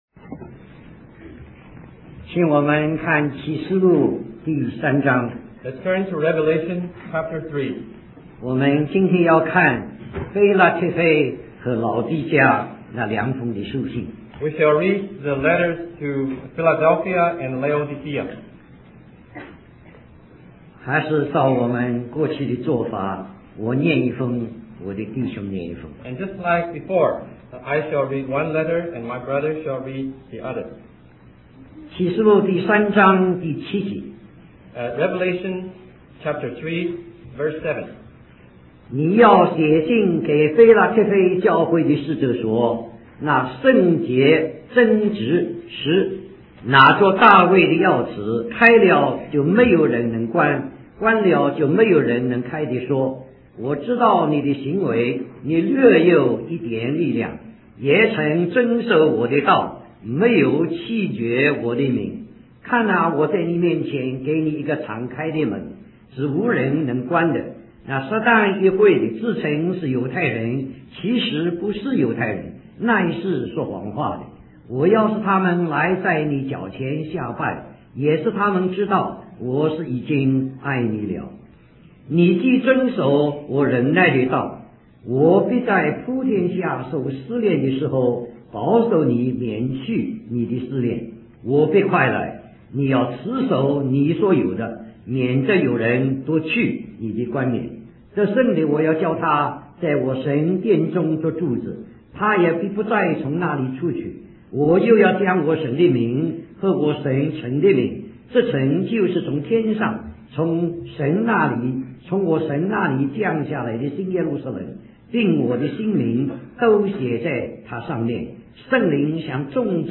A collection of Christ focused messages published by the Christian Testimony Ministry in Richmond, VA.
Conference at Bible Institute of Los Angeles